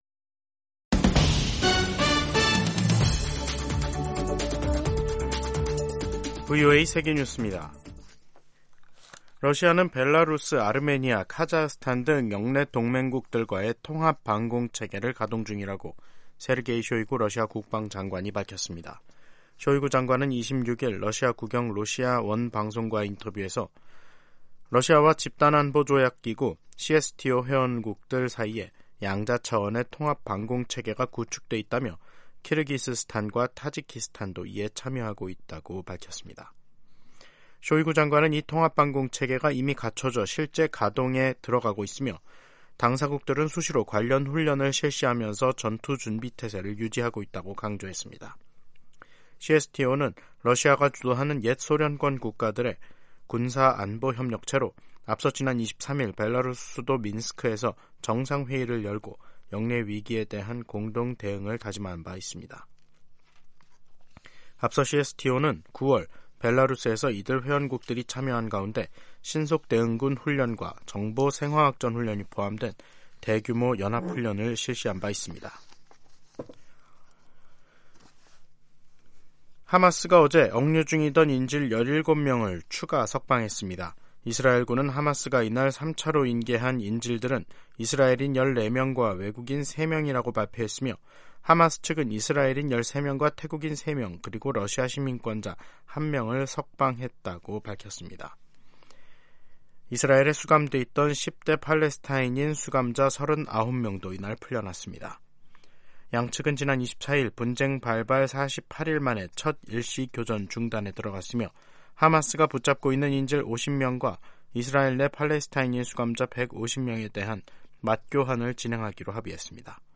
세계 뉴스와 함께 미국의 모든 것을 소개하는 '생방송 여기는 워싱턴입니다', 2023년 11월 27일 저녁 방송입니다. '지구촌 오늘'에서는 하마스가 억류한 인질과 이스라엘에 수감된 팔레스타인 수감자가 24일부터 연이어 석방된 가운데 하마스가 휴전 연장을 원한다는 소식 전해드리고, '아메리카 나우'에서는 '블랙 프라이데이' 온라인 매출이 98억 달러로 역대 최대를 기록한 이야기 살펴보겠습니다.